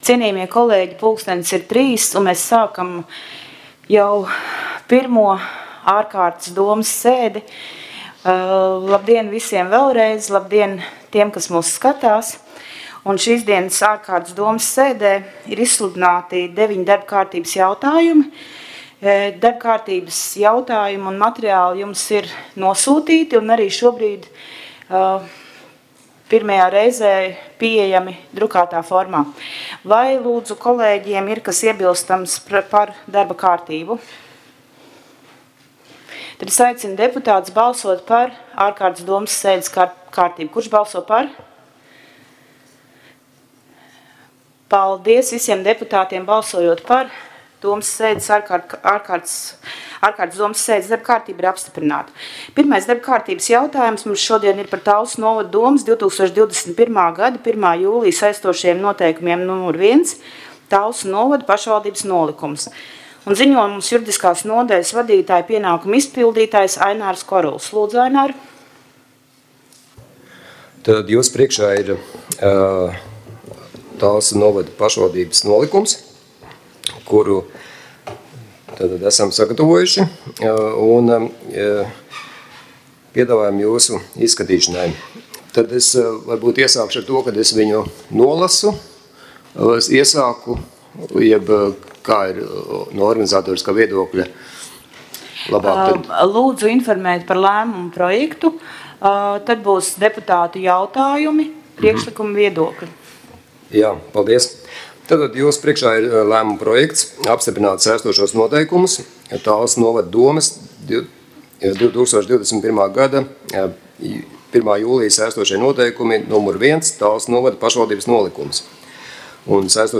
2. Talsu novada domes ārkārtas sēdes protokols